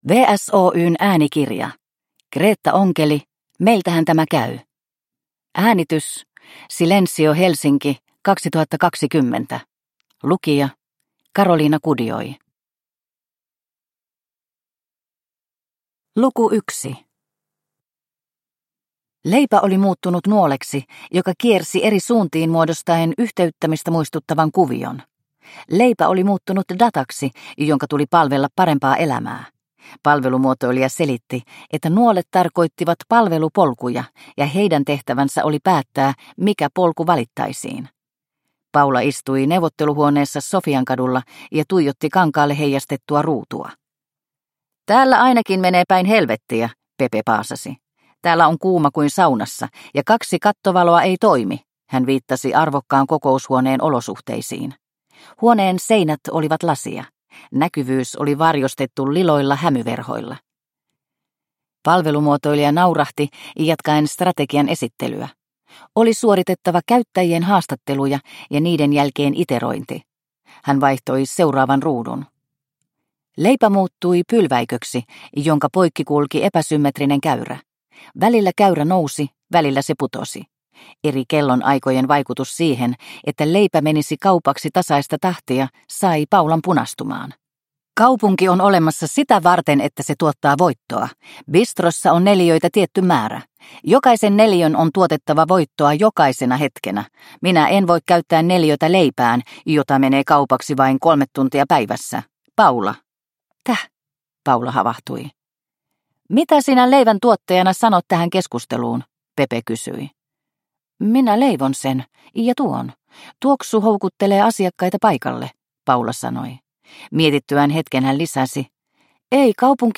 Meiltähän tämä käy – Ljudbok – Laddas ner